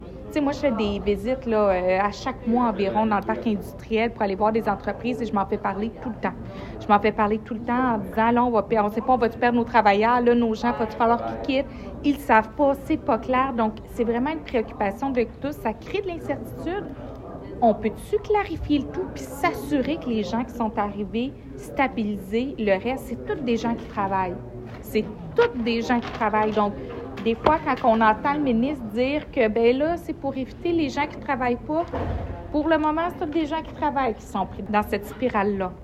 Julie Bourdon, mairesse.